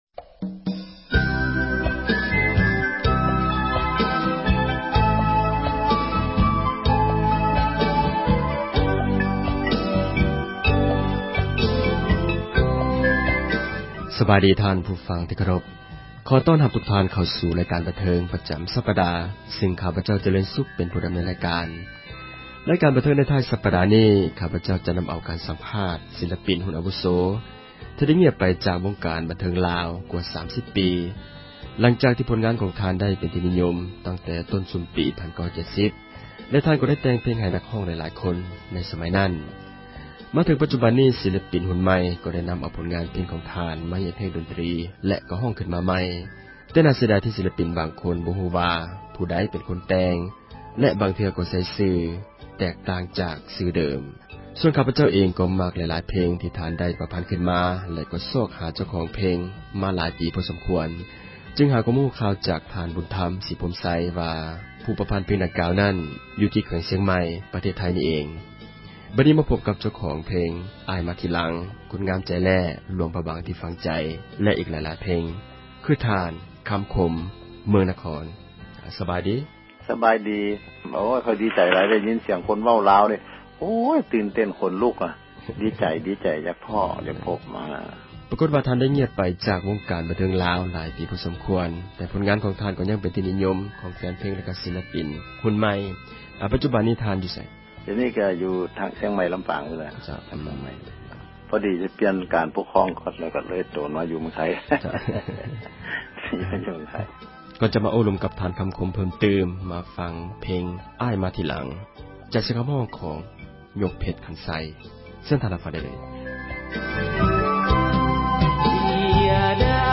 ການສັມພາດ ນັກແຕ່ງເພງ